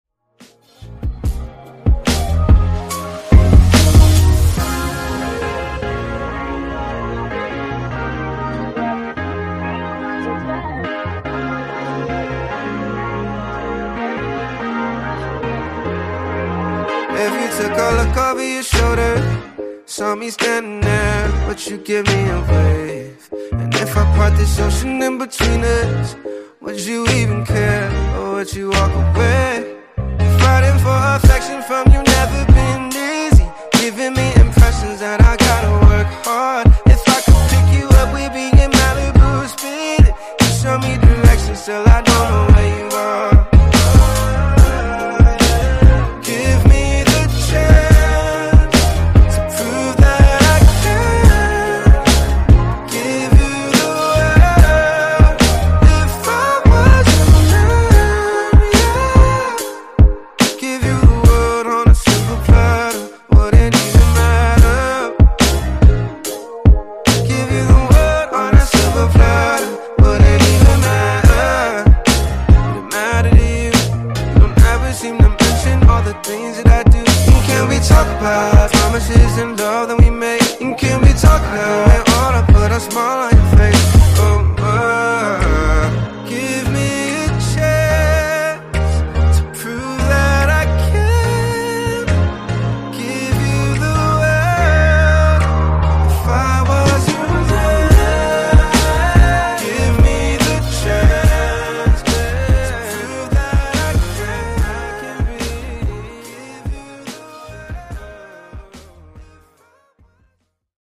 Genre: RE-DRUM Version: Clean BPM: 121 Time